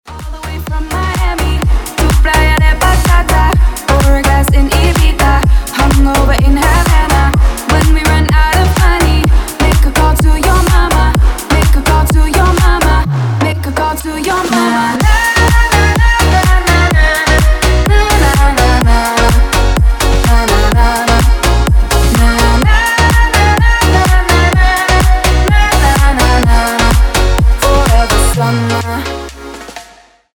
• Качество: 320, Stereo
позитивные
зажигательные
веселые
заводные
future house
Dance Pop